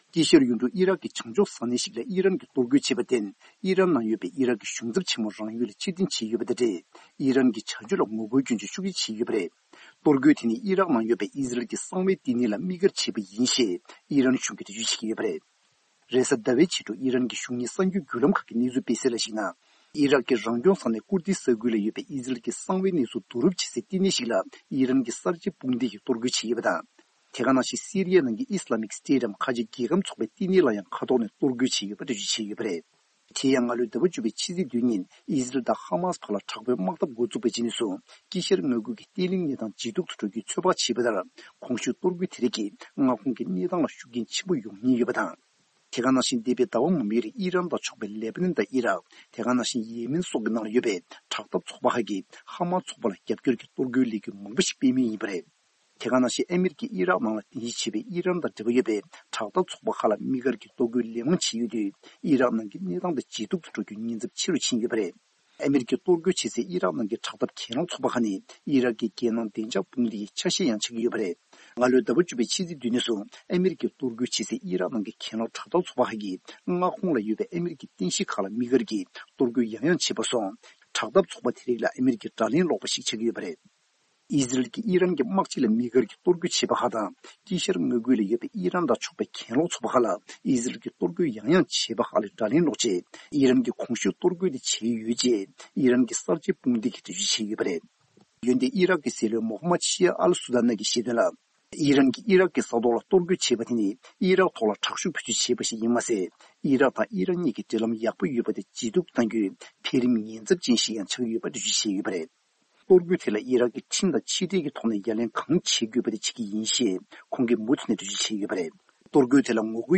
གནས་ཚུལ་སྙན་སྒྲོན་ཞུ་ཡི་རེད།